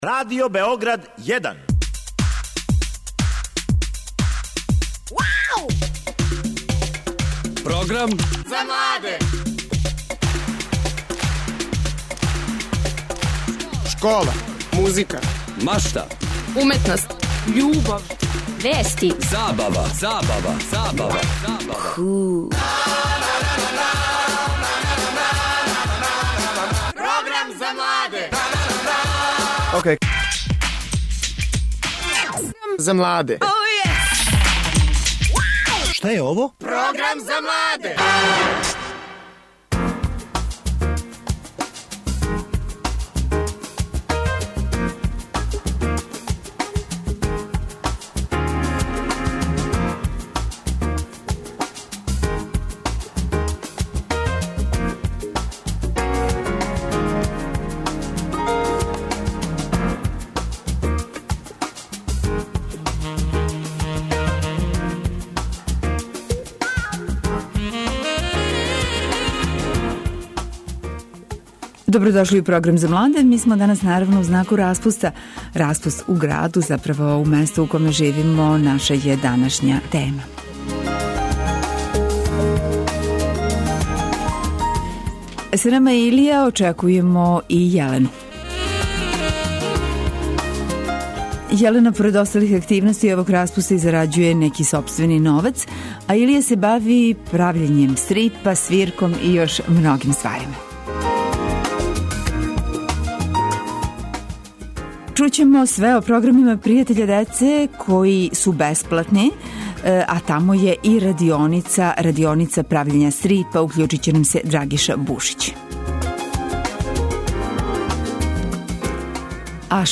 У емисији ћете чути студенте који су отпутовали на скијање у Француску, а сазнаћете и како распуст проводе основци и средњошколци широм Србије.